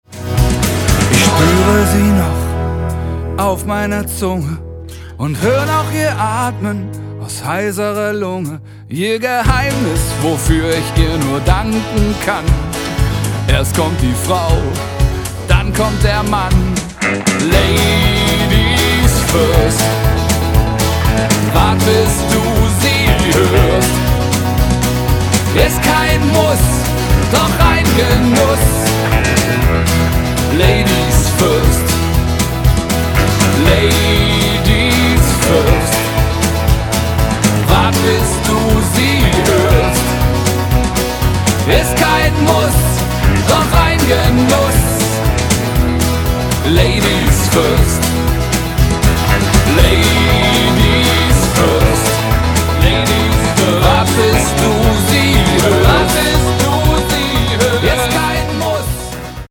einen spritzigen deutschen Rocksong
für Freunde von Country & Western